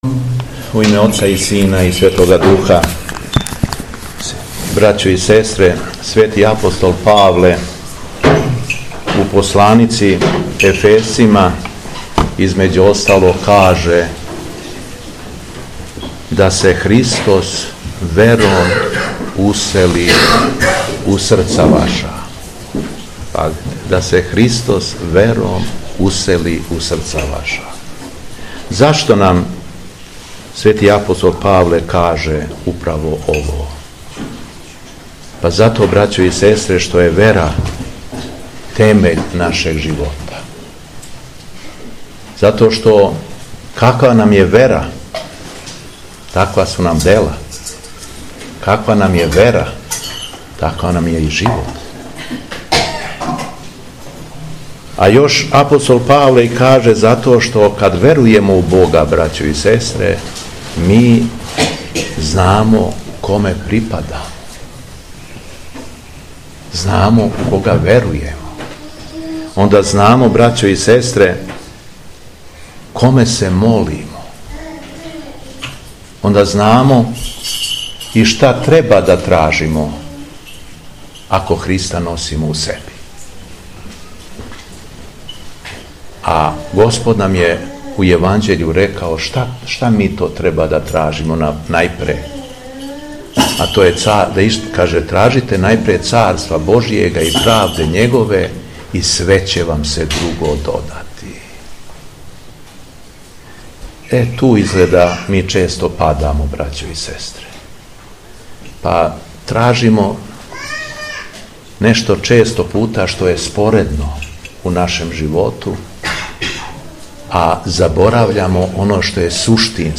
Беседа Његовог Високопреосвештенства Митрополита шумадијског г. Јована
Велики број верника присуствовао је Светој Литургији, а митрополит се окупљеном народу обратио богонадахнутом беседом.